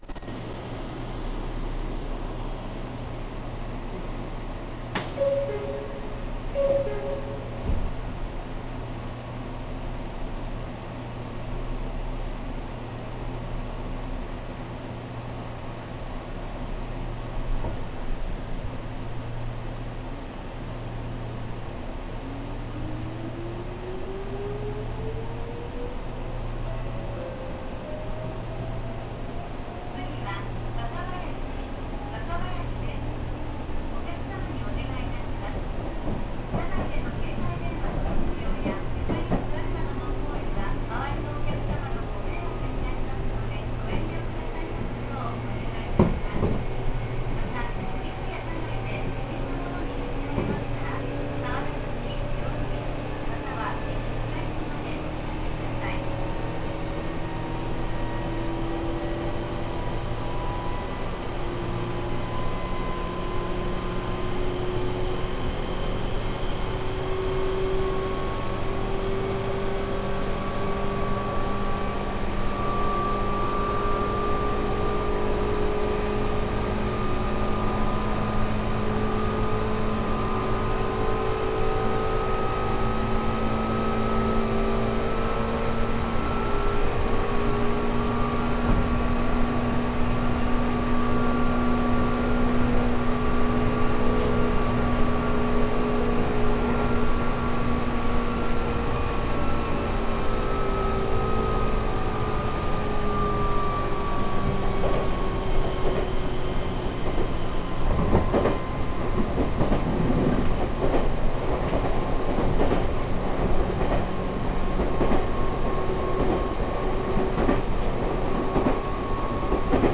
・6000系走行音
【三河線】三河八橋→若林（3分3秒：1.4MB）
いかにも抵抗制御、という音。103系の音にも似ている気がします。
意外と三河線は飛ばす区間があるようなので、収録にはお勧めかもしれません。
6000_MikawaYatsuhashi-Wakabayashi.mp3